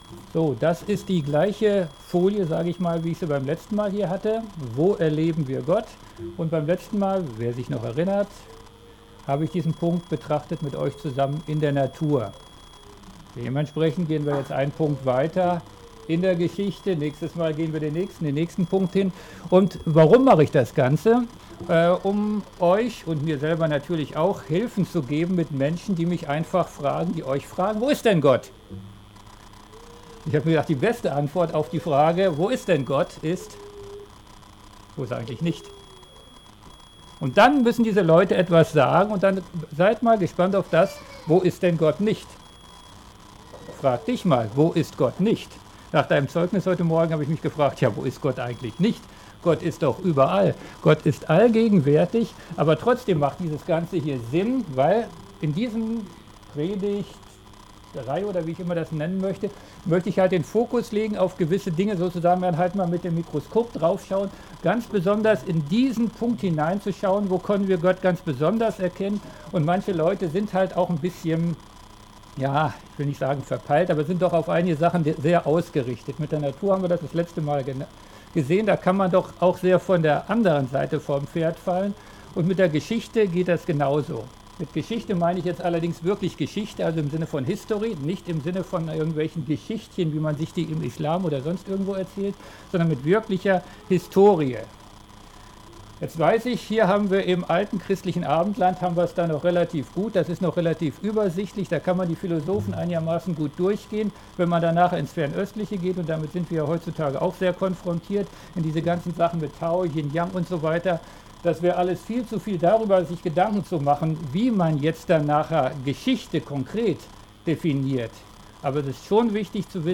MP3 Predigten